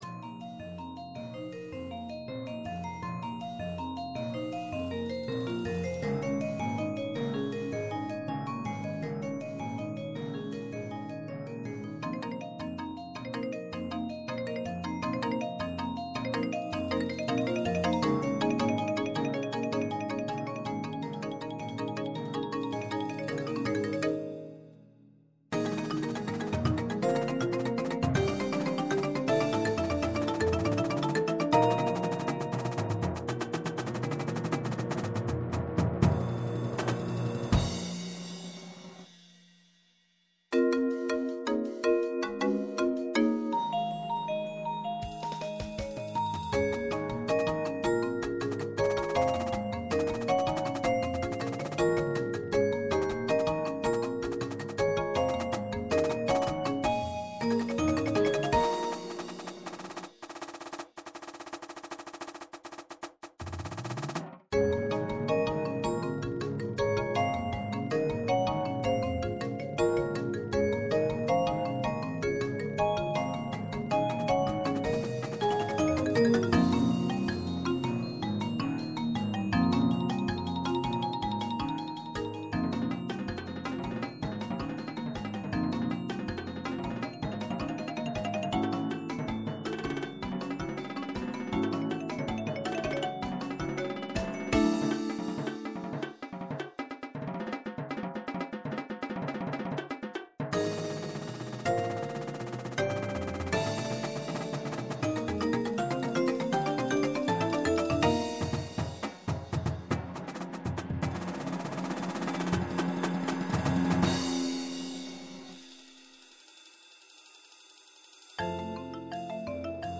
Bells
Xylophone
Marimba (2)
Vibes (2)
Chimes
Timpani
Synth
Drum Set
Auxiliary Percussion
Snare
Tenors (quints)
Bass Drums (5)
Cymbals